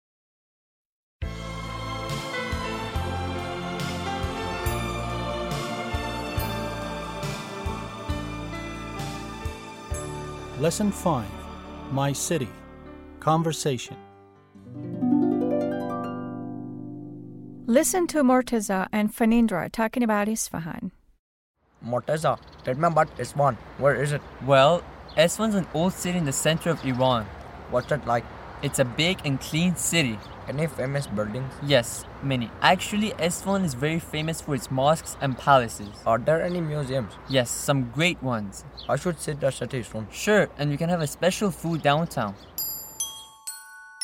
8-L5-Conversation
8-L5-Conversation.mp3